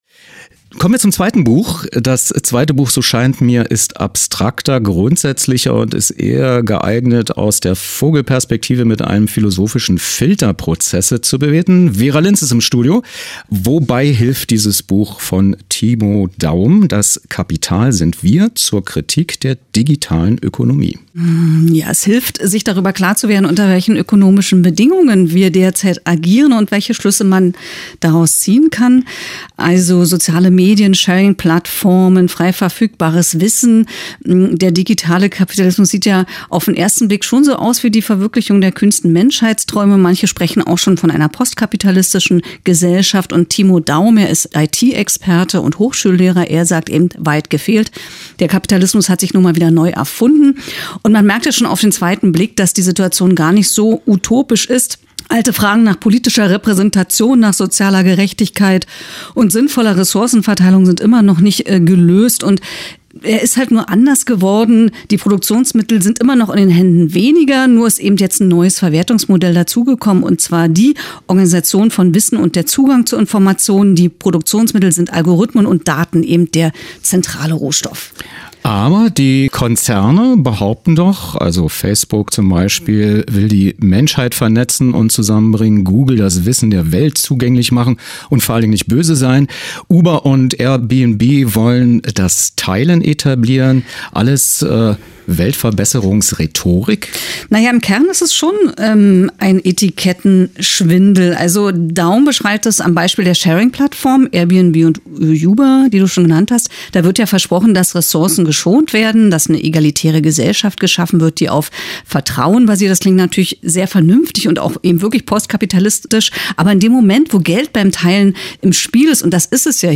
Was: Studiogespräch zum Buch
Wo: radioeins-Sendestudio, Medienstadt Babelsberg